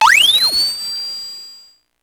Index of /90_sSampleCDs/300 Drum Machines/Electro-Harmonix Spacedrum
Drum12.wav